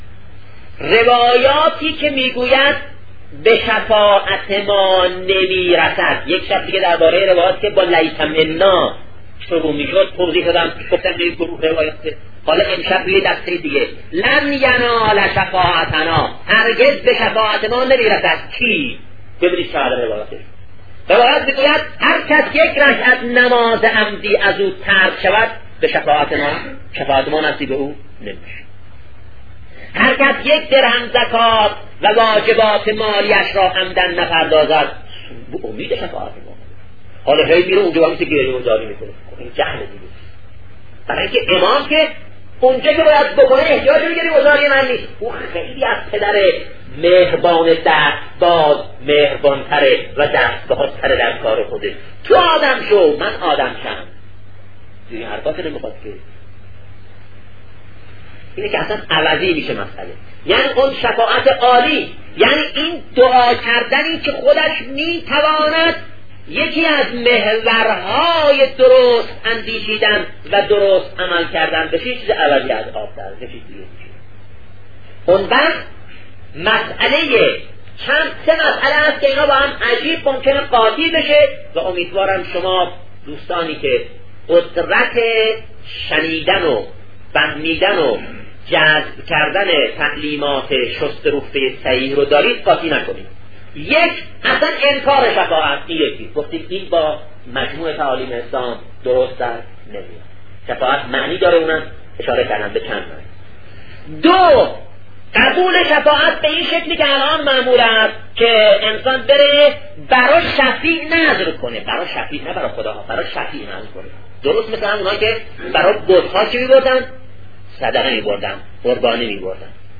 آیا این سخنرانی از شهید بهشتی است؟